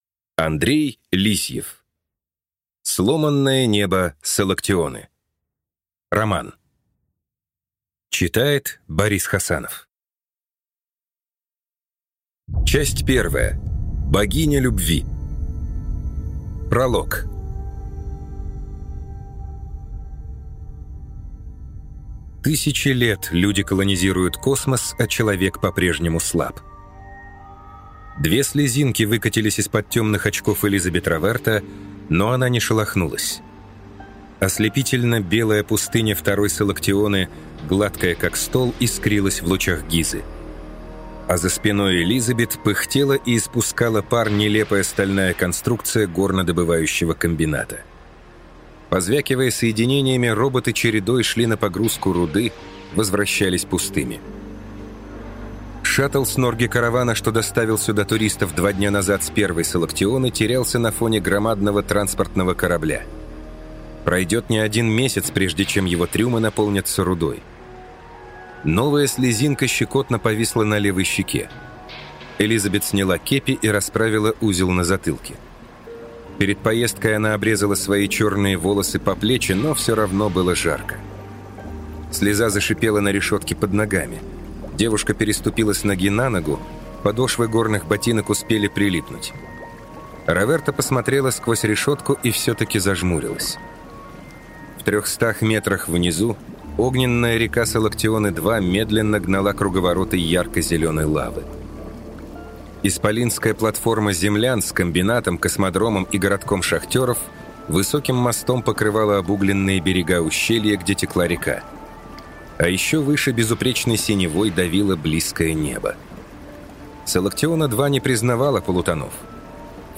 Aудиокнига Сломанное небо Салактионы